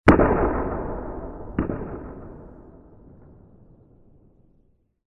Звуки эха
Выстрел из ружья в тайге с расстилающимся эхом